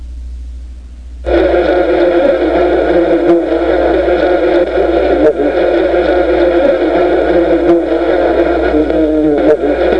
a_bee.mp3